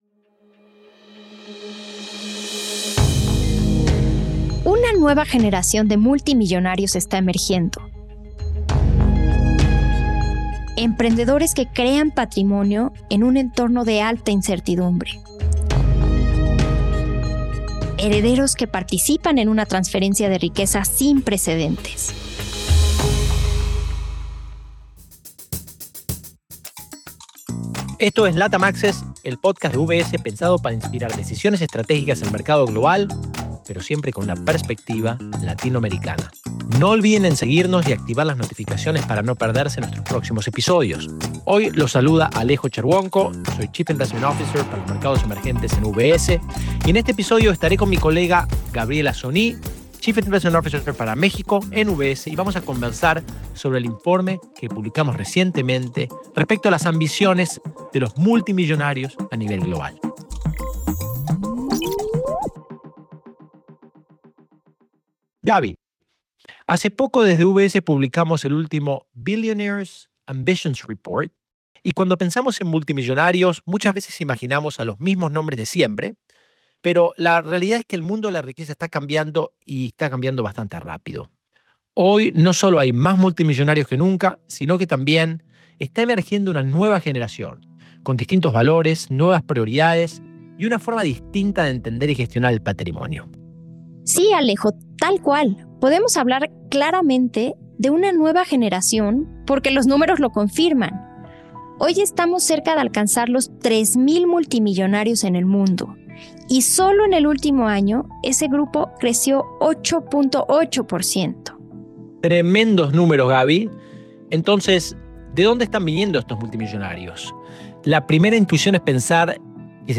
conversan sobre el Billionaires Report de UBS y los cambios que trae una nueva generación de multimillonarios en la riqueza global.